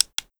MenuSFX3.wav